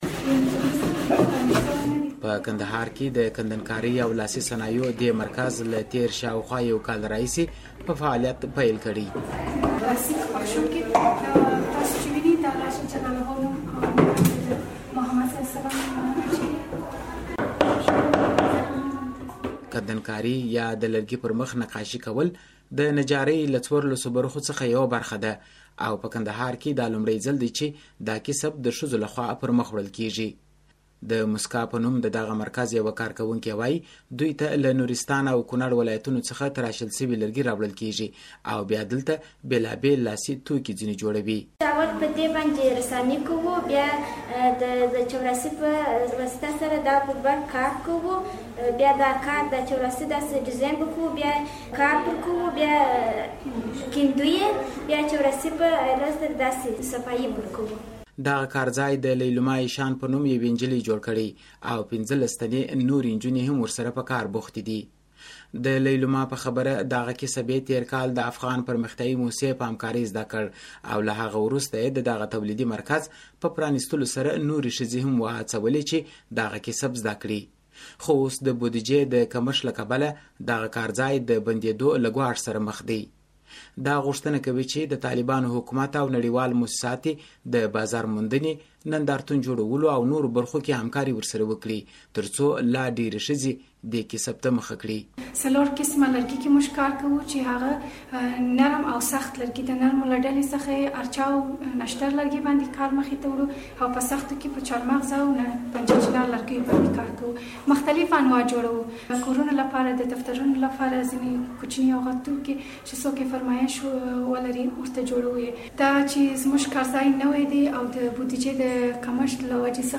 د کندهار راپور